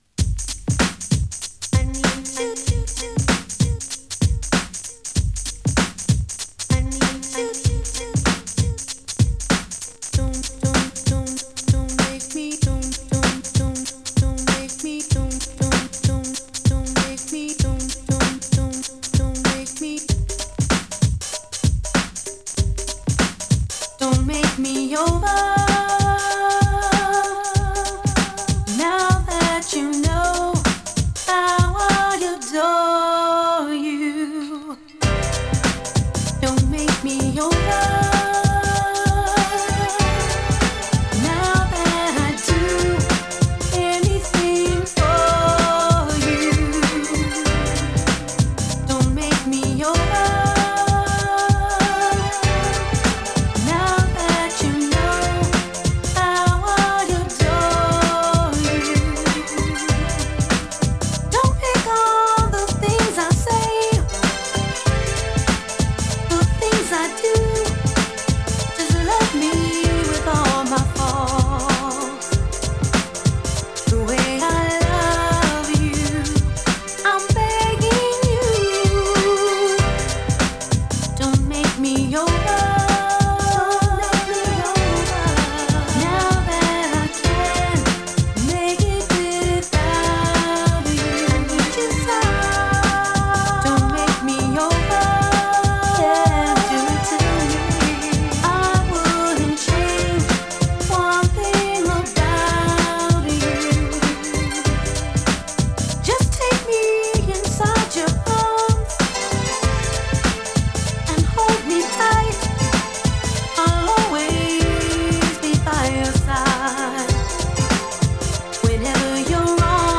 切なくもブライトな雰囲気が漂うグランドビート！ ニュージャージー出身の女性シンガーによる